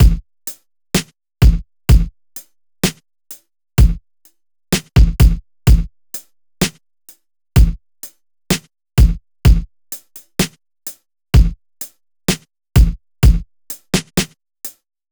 14 drums C1.wav